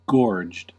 Ääntäminen
Synonyymit full jaded Ääntäminen US RP : IPA : /ɡɔːdʒd/ US : IPA : /ɡɔːrdʒd/ Haettu sana löytyi näillä lähdekielillä: englanti Käännöksiä ei löytynyt valitulle kohdekielelle.